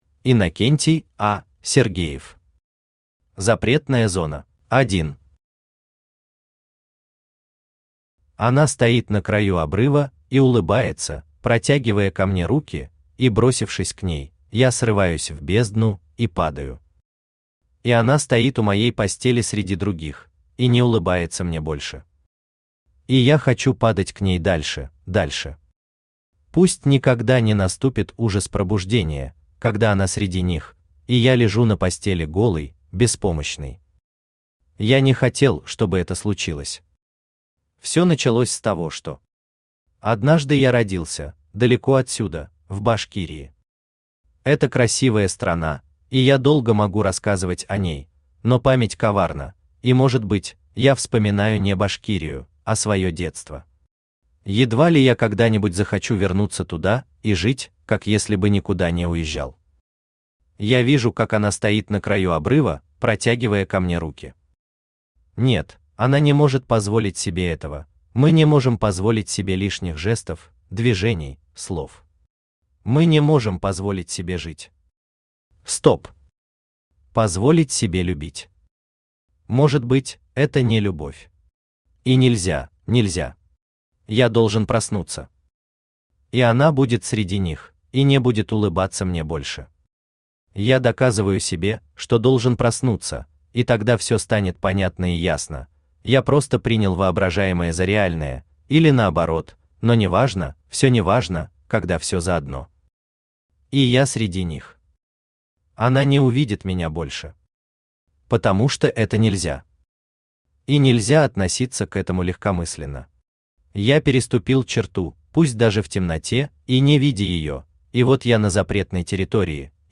Аудиокнига Запретная зона | Библиотека аудиокниг
Aудиокнига Запретная зона Автор Иннокентий А. Сергеев Читает аудиокнигу Авточтец ЛитРес.